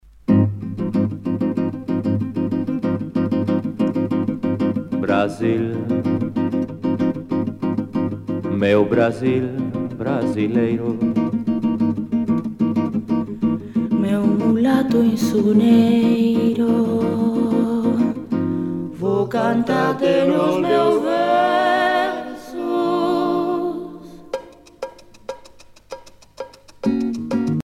danse : bossa nova
Pièce musicale éditée